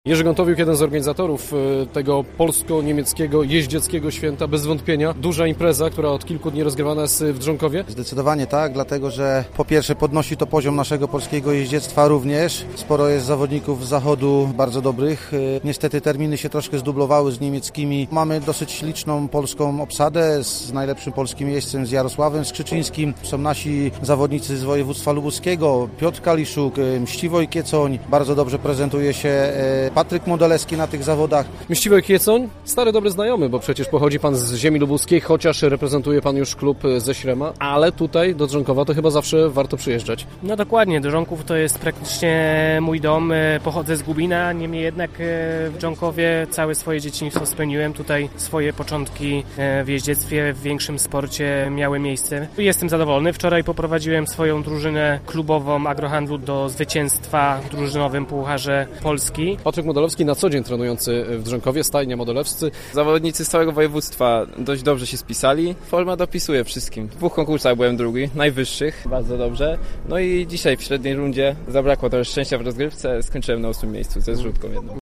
My rozmawialiśmy z głównymi bohaterami tej imprezy: